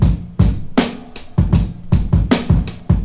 104 Bpm Drum Loop G# Key.wav
Free breakbeat - kick tuned to the G# note. Loudest frequency: 956Hz
104-bpm-drum-loop-g-sharp-key-tfd.ogg